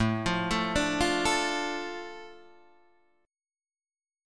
Asus4#5 chord {5 5 3 2 3 5} chord